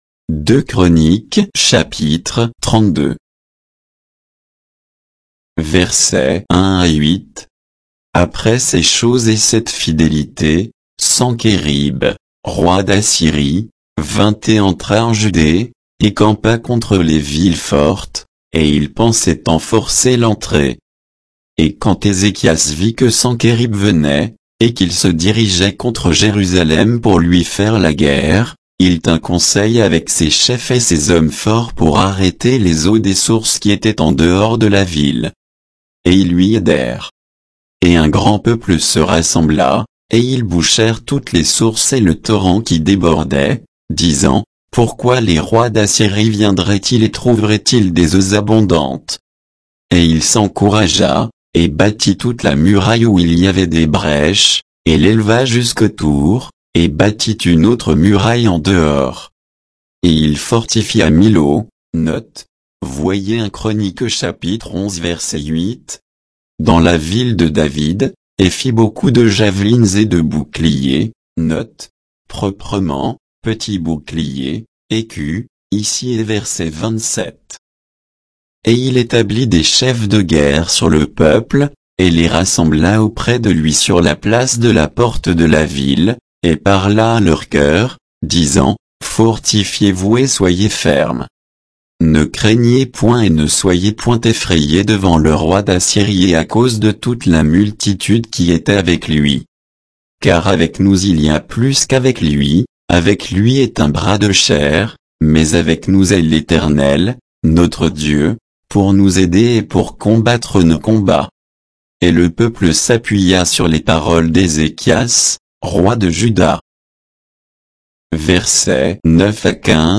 Bible_2_Chroniques_32_(avec_notes_et_indications_de_versets).mp3